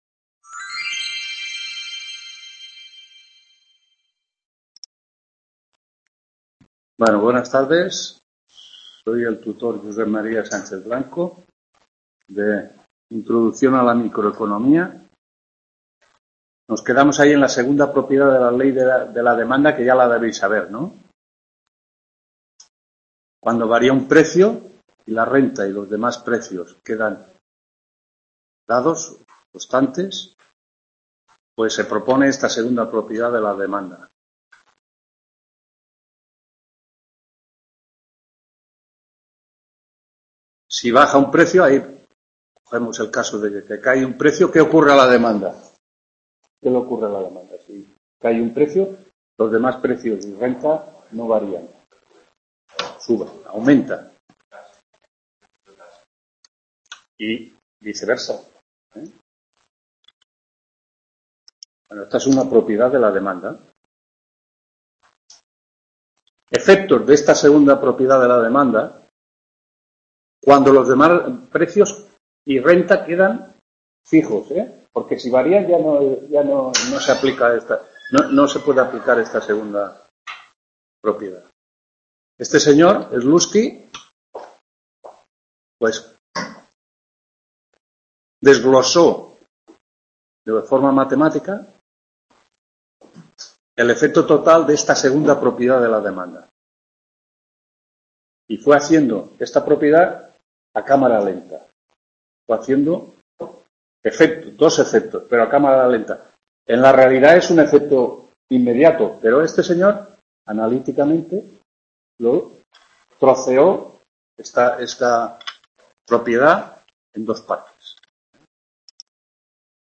6ª TUTORÍA INTRODUCCIÓN A LA MICROECONOMÍA ADE